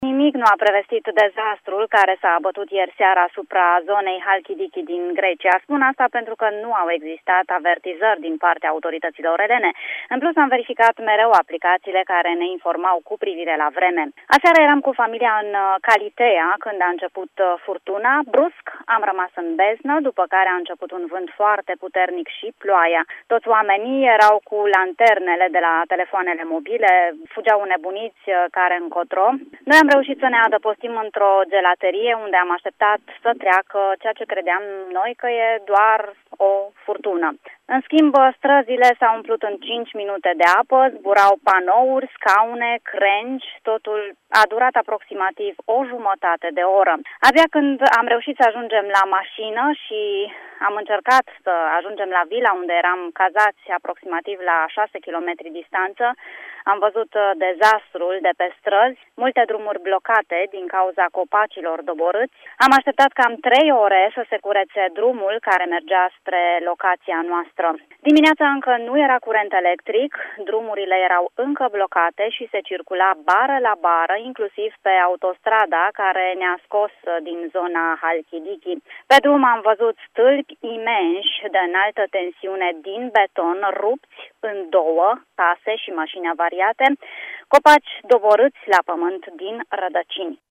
a trăit aceste momente din perspectiva turistului aflat chiar în acel moment în Halkidiki